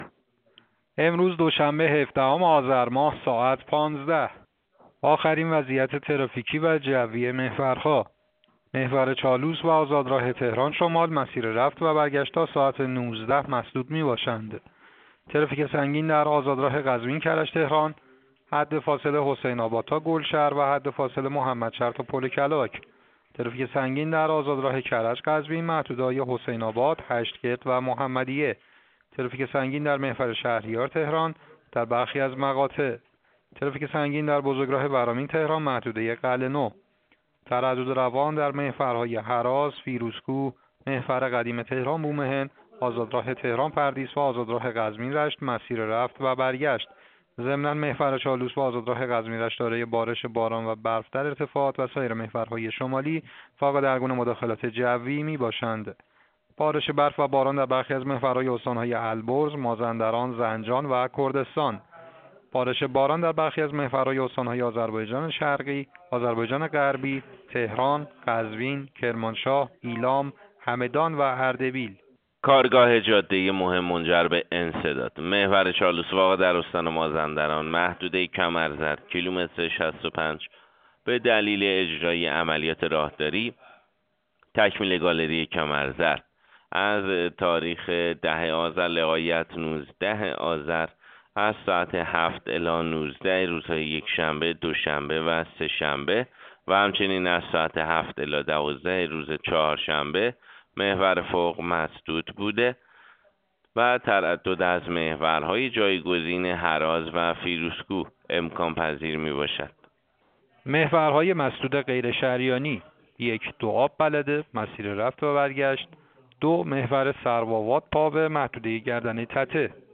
گزارش رادیو اینترنتی از آخرین وضعیت ترافیکی جاده‌ها ساعت ۱۵ هفدهم آذر؛